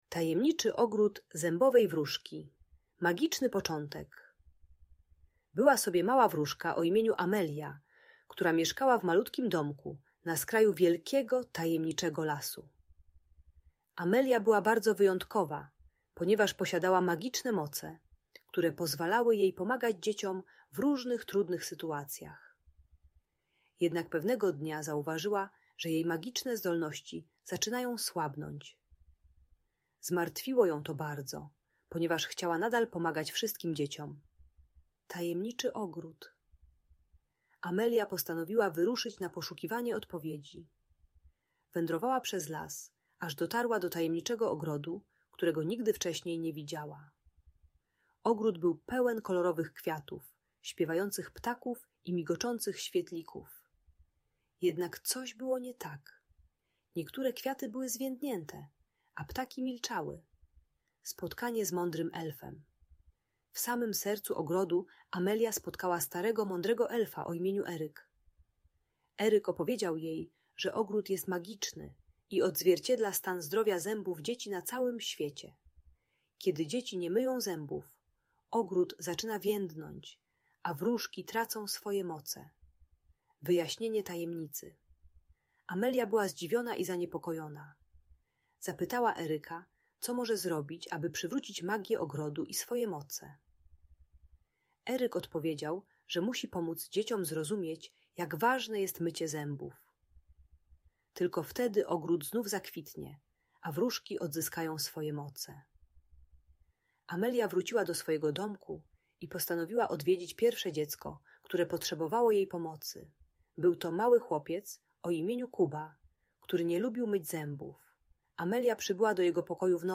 Tajemniczy ogród zębowej wróżki - magiczna historia - Audiobajka